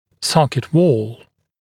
[‘sɔkɪt wɔːl][‘сокит уо:л]стенка зубной ячейки, зубной альвеолы